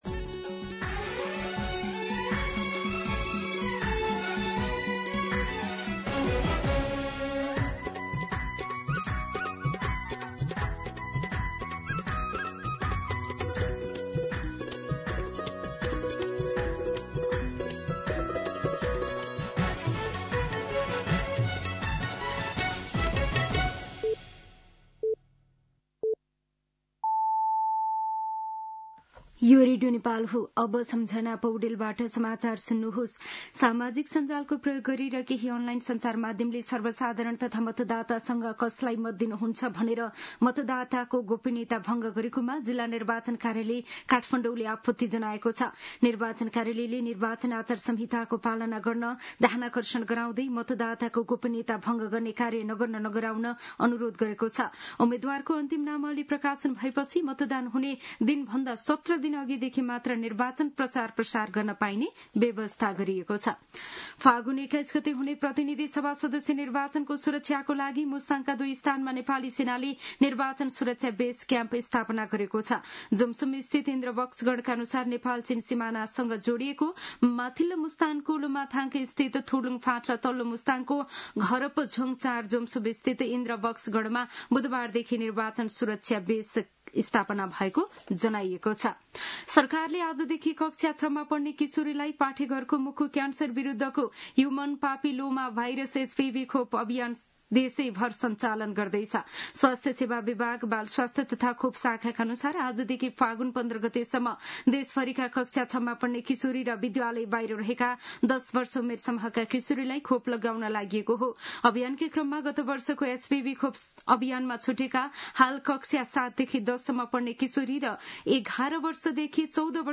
दिउँसो १ बजेको नेपाली समाचार : २५ माघ , २०८२